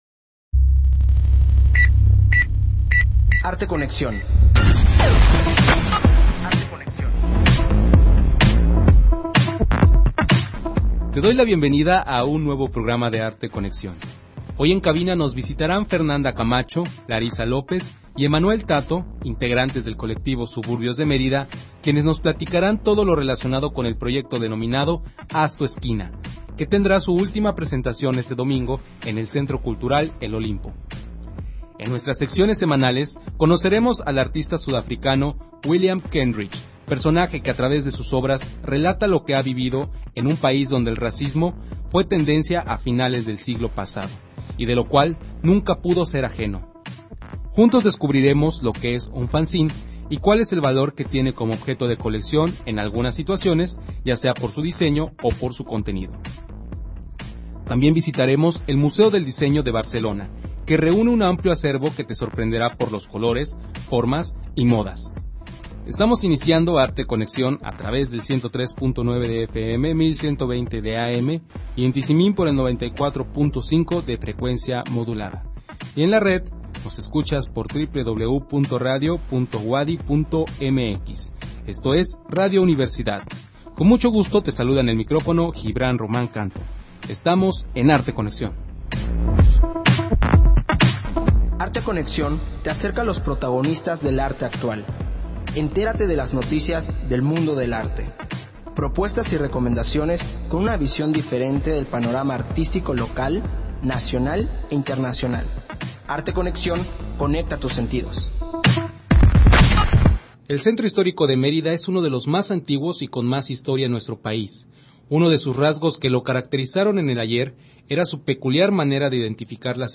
Entrevista con el colectivo Suburbios de Mérida / Parte 1
En esta ocasión nos acompañaron en cabina los integrantes del colectivo Suburbios de Mérida para platicarnos sobre "Haz tu esquina", proyecto cuya finalidad es rescatar las historias y el valor que poseen las esquinas del centro histórico de la capital yucateca.
entrevista-con-el-colectivo-suburbios-de-merida--parte-1